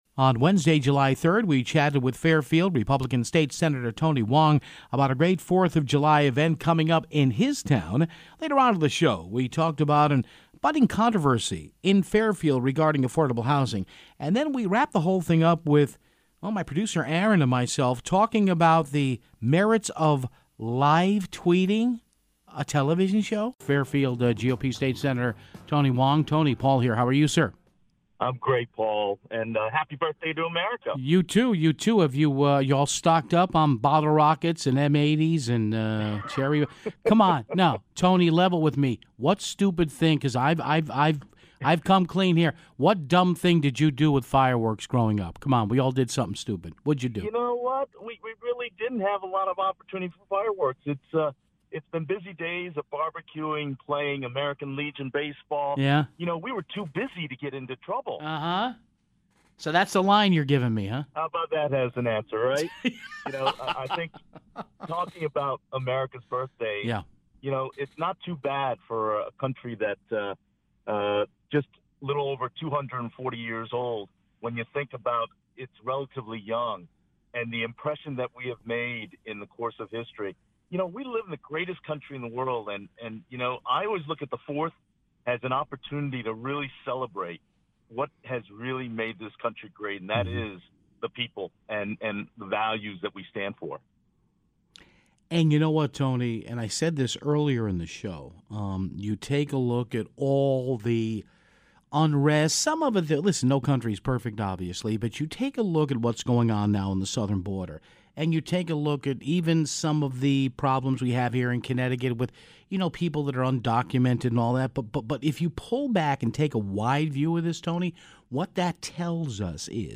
A pair of callers chime in on a recent lawsuit against the gun manufacturer of the Las Vegas shooting in 2017.